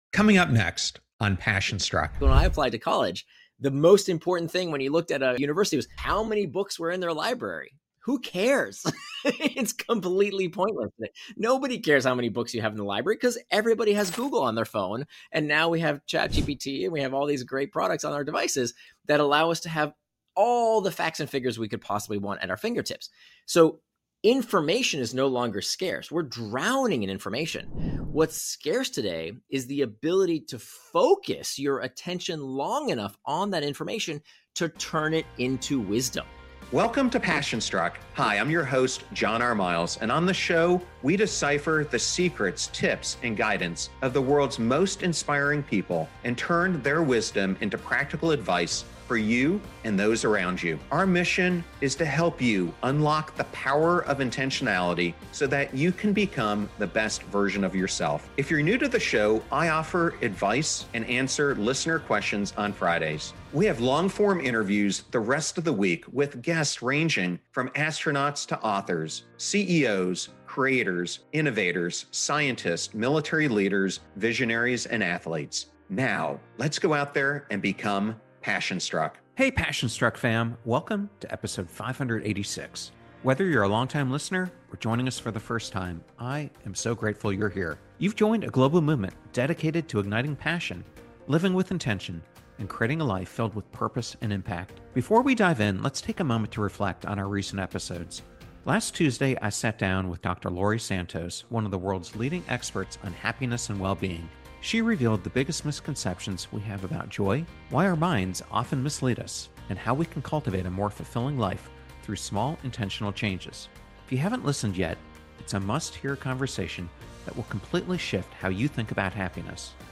Nir shares actionable strategies to help you break free from interruptions, boost productivity, and take back control of your time. If you’ve ever struggled with procrastination or felt overwhelmed by endless distractions, this conversation will give you the tools to become truly indistractable.